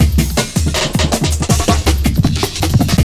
53JUNGL160.wav